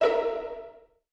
ihob/Assets/Extensions/CartoonGamesSoundEffects/Suspicious_v1/Suspicious_v2_wav.wav at master
Suspicious_v2_wav.wav